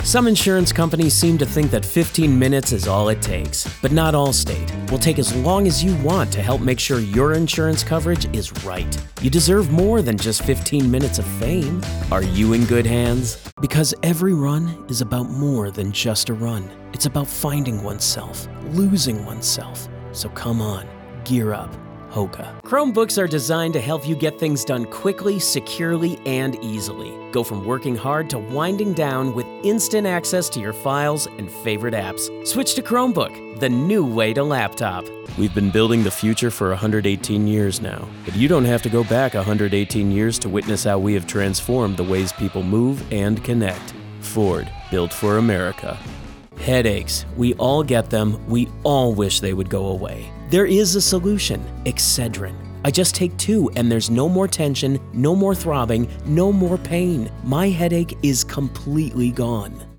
Commercial
English - Midwestern U.S. English